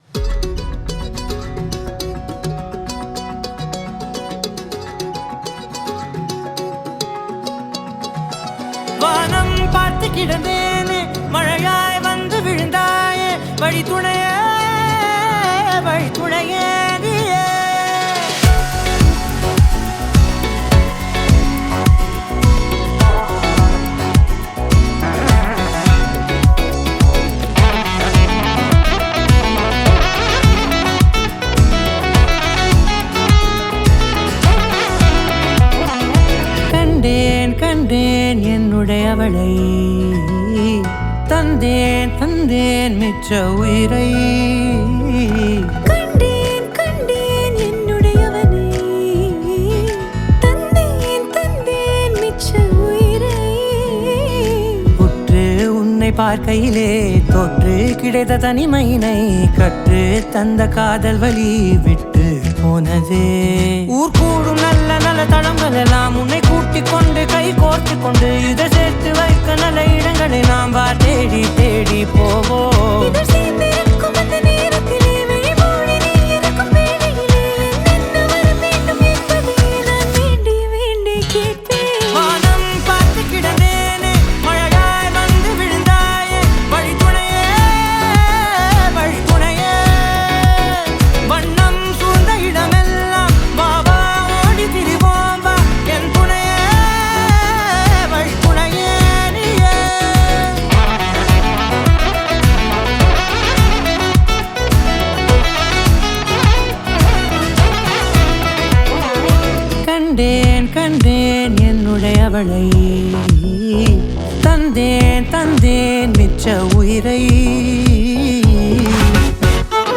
Acoustic, Electric & Bass Guitar
Violin
Mandolin
Mridangam
Synths & Rhythm Programming